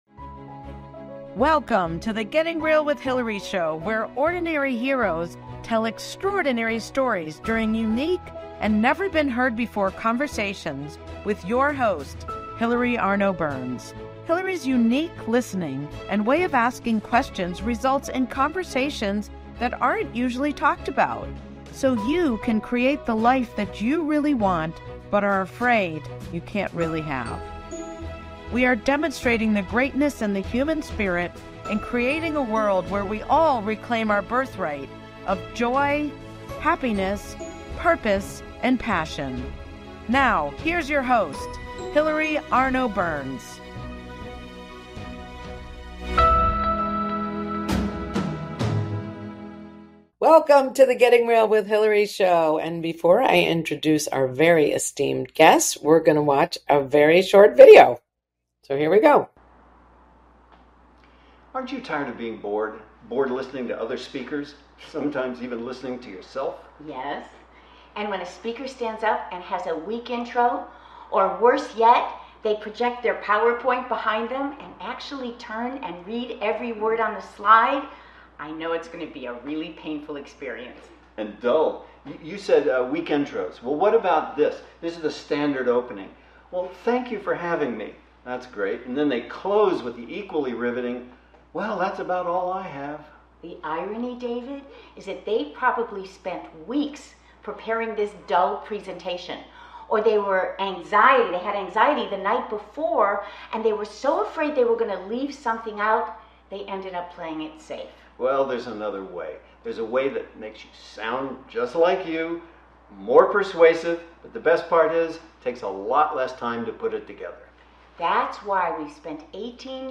Talk Show Episode, Audio Podcast
I delivered a speech during our interview with only 10 seconds of advance warning.